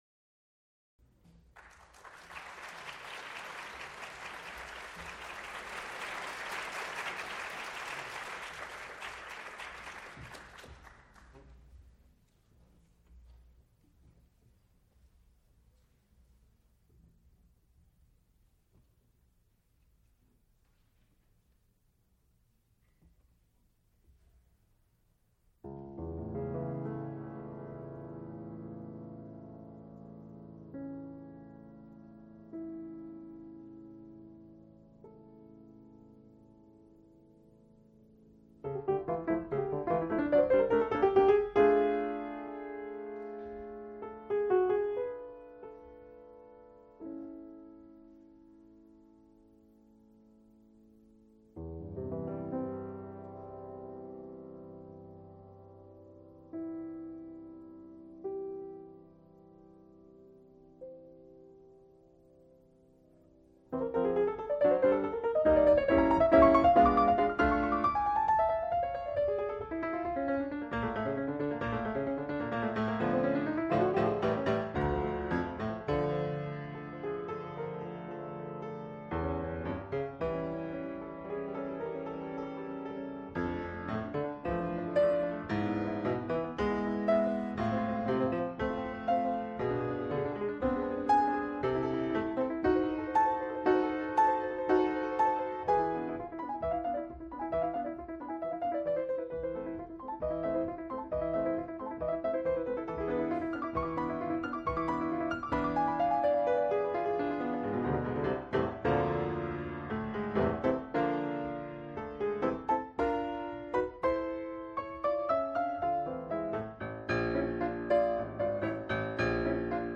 piano
Sonatas (Piano), Piano music
Recorded live January 20, 1981, Frick Fine Arts Auditorium, University of Pittsburgh.
Music Department, University of Pittsburgh (depositor) Date 1981, 1981-01-20, [1981] Type Sound, musical performances Format sound recording, sound-tape reel, audio, audiotape reel, 4 audiotape reels : analog, half track, stereo, 7 1/2 ips ; 7 in.